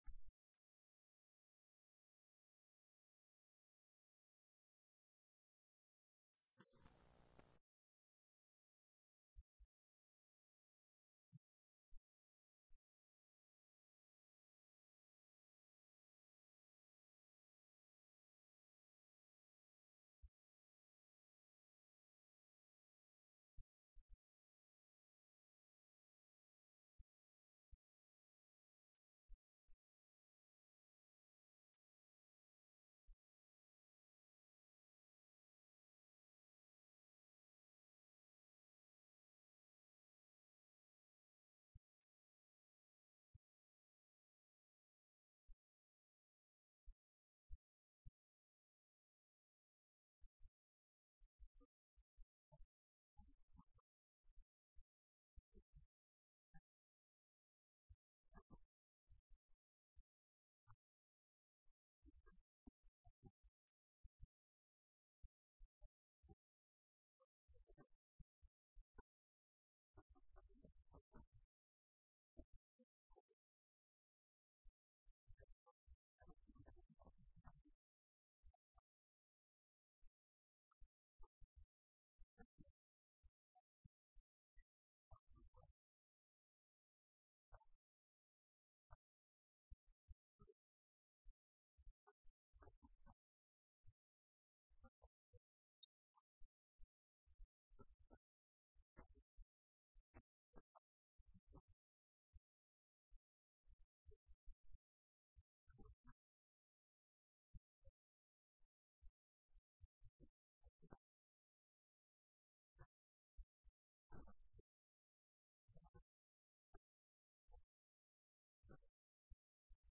Assamese-News-1205.mp3